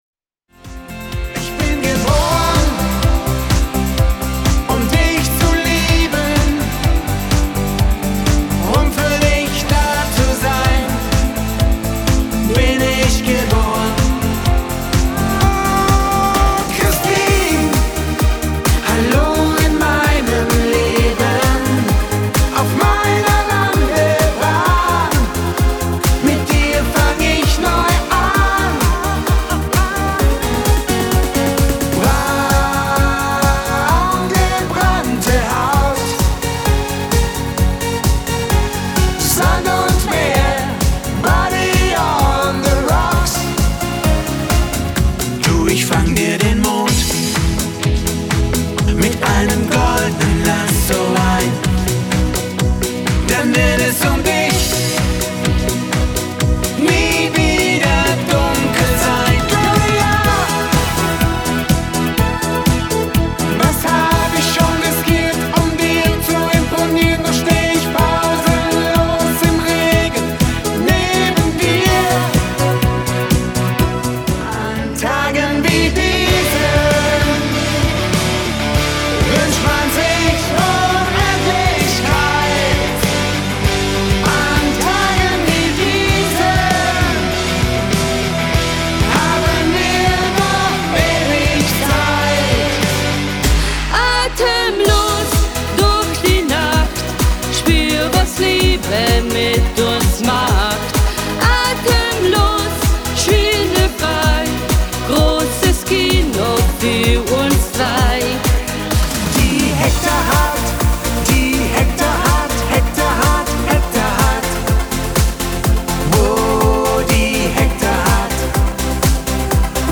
Quartett